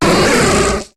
Fichier:Cri 0181 HOME.ogg — Poképédia
Cri de Pharamp dans Pokémon HOME.